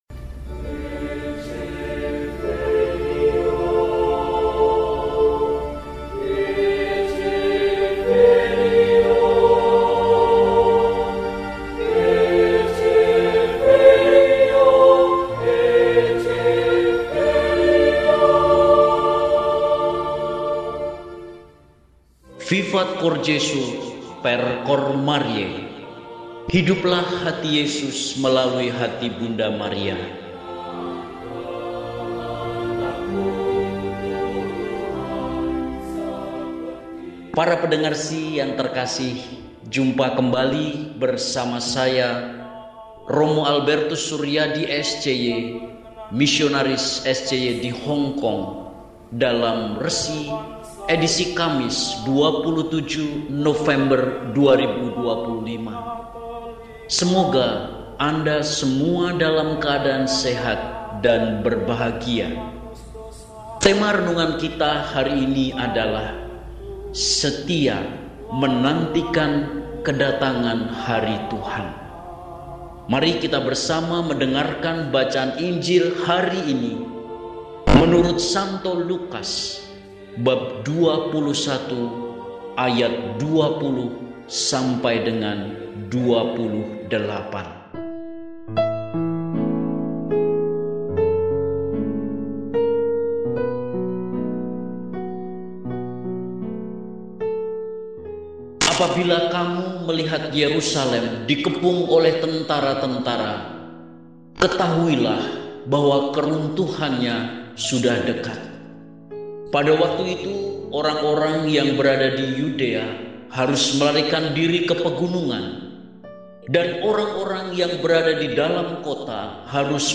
Kamis, 27 November 2025 – Hari Biasa Pekan XXXIV – RESI (Renungan Singkat) DEHONIAN